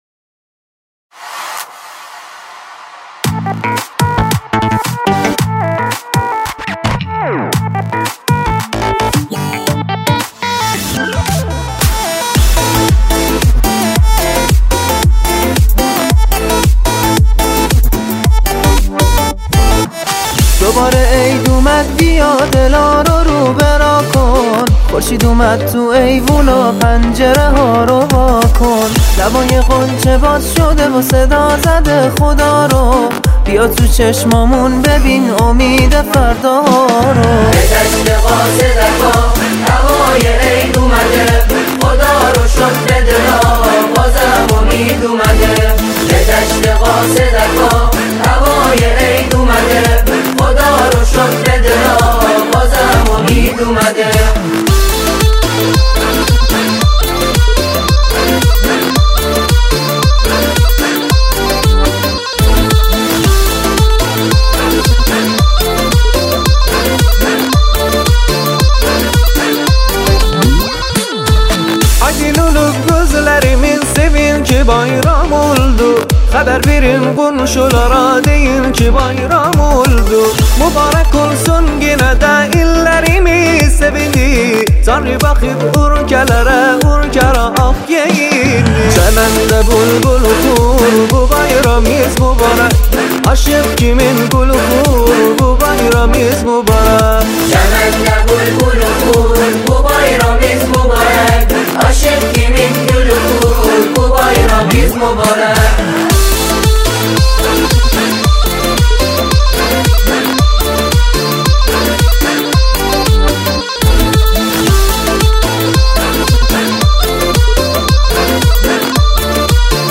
سرودهای عیدانه